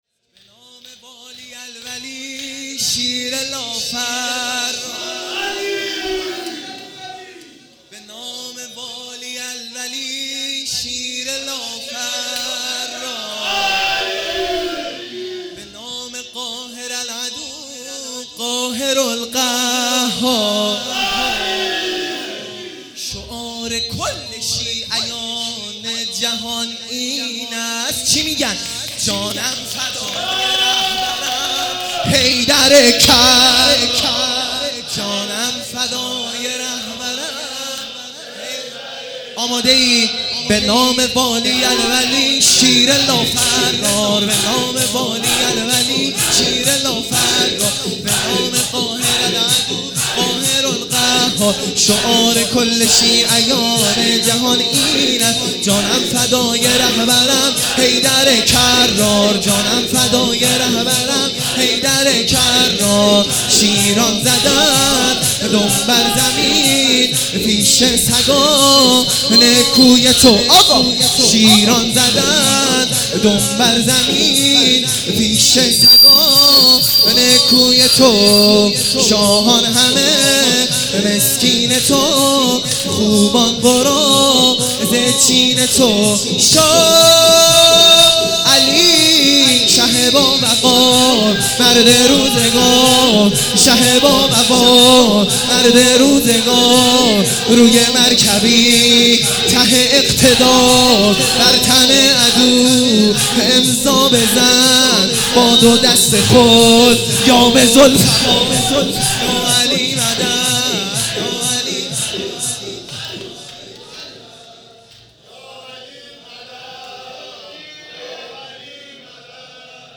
خیمه گاه - بیرق معظم محبین حضرت صاحب الزمان(عج) - شور ا شعار کل شیعیان جهان